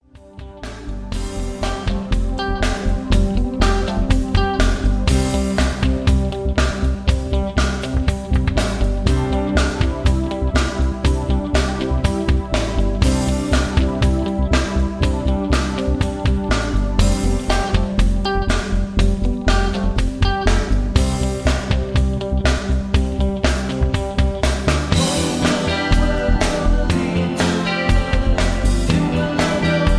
Key-C) Karaoke MP3 Backing Tracks
Just Plain & Simply "GREAT MUSIC" (No Lyrics).